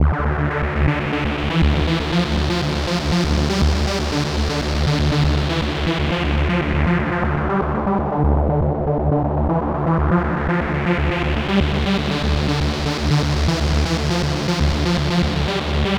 komposter_supersaw.wav